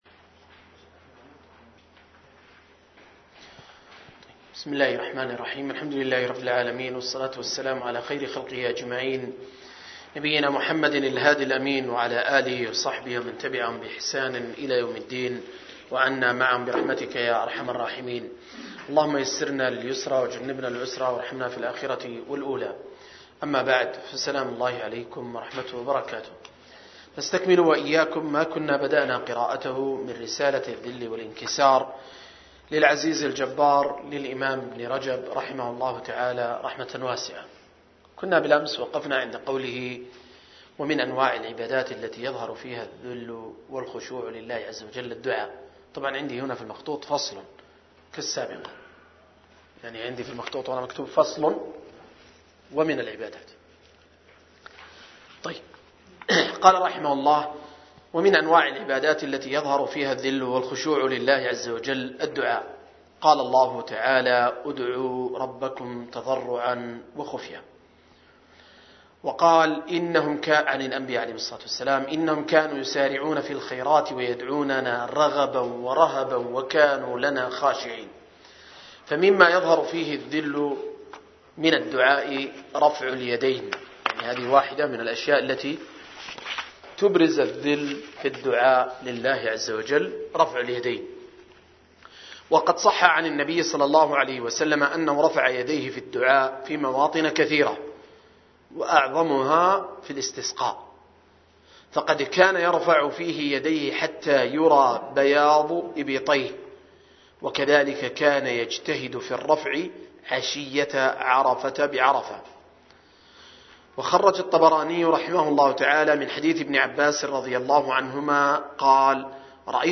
رسالة الذل والانكسار للعزيز الجبار للإمام الحافظ ابن رجب الحنبلي رحمه الله – قراءة وتعليق – المجلس الثالث